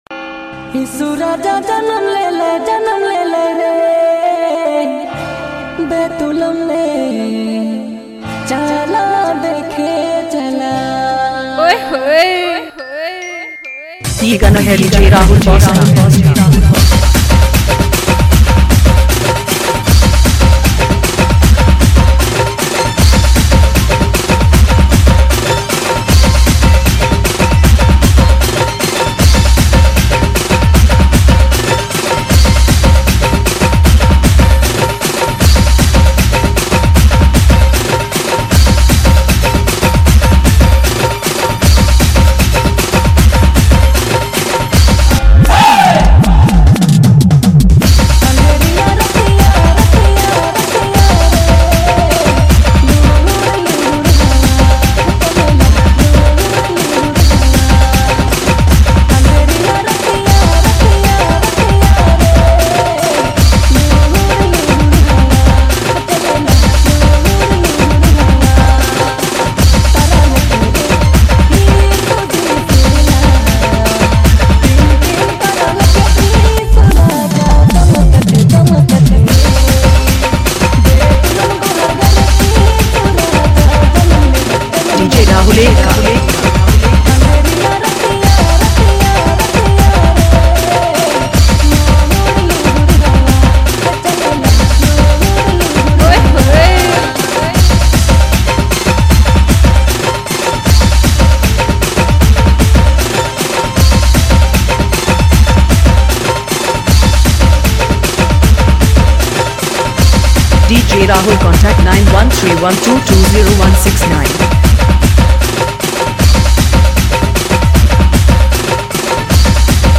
Christmas Dhanka Mix Song